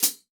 14HK FOOT.wav